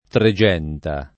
Tregenta [ tre J$ nta ] → Trexenta